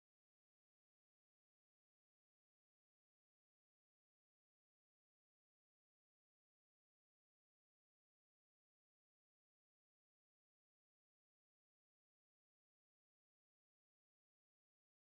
刀剣乱舞 雰囲気真似一覧